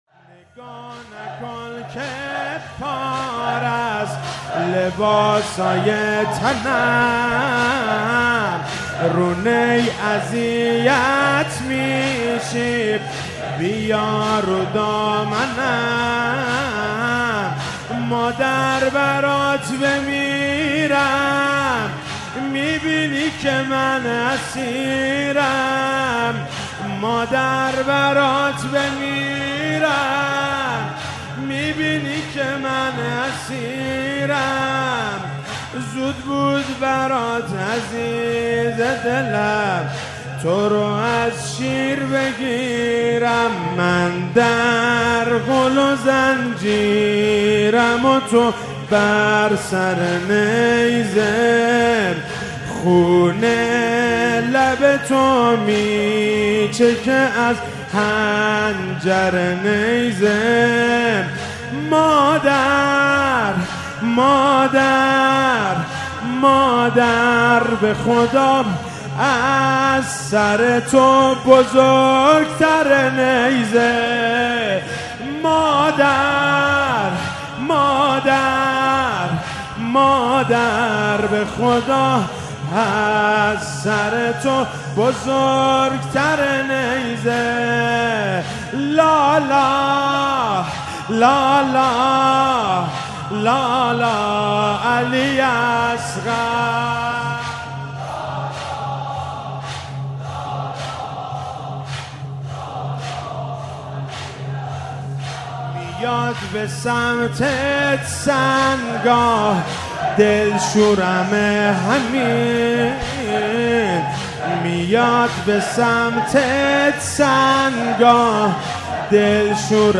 شب هفتم محرم96 -زمینه - نگاه نکن که پارست لباسای تنم